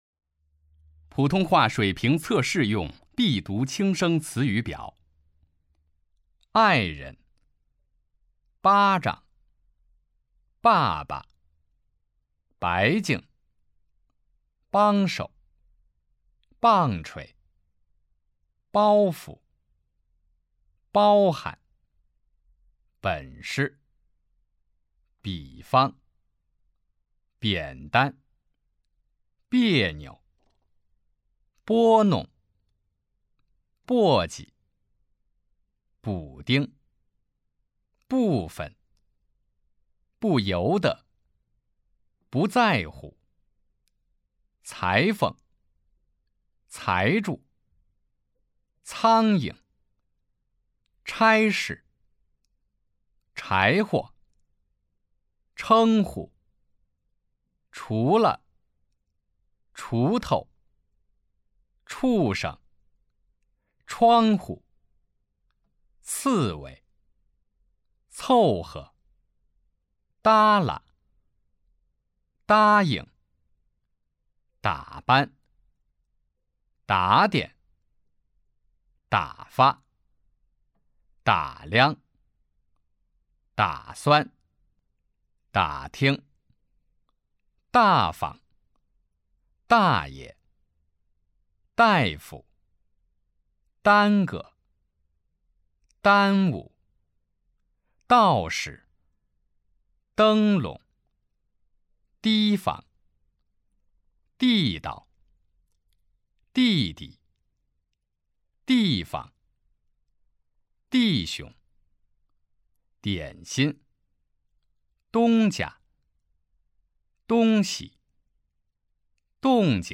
普通话水平测试 > 普通话水平测试资料包 > 02-普通话水平测试提升指导及训练音频
009普通话水平测试用必读轻声词语表.mp3